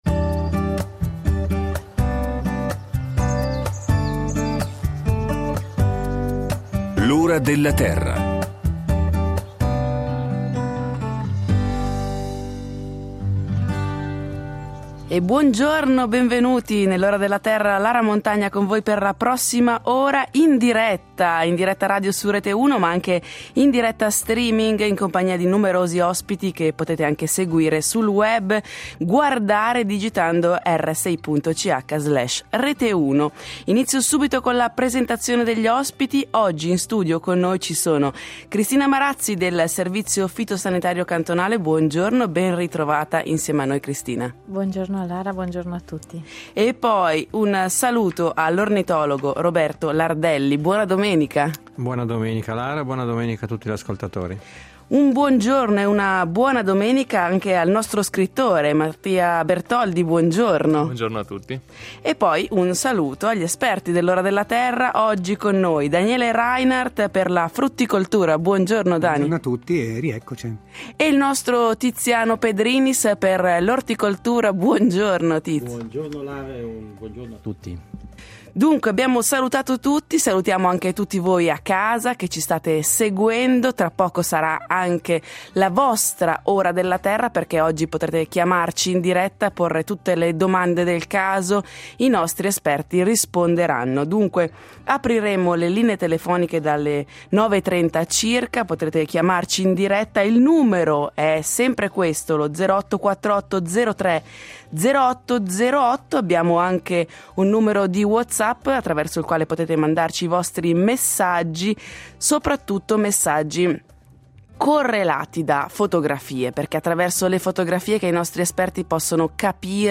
rispondono alle domande del pubblico in diretta. Orticoltura e frutticoltura.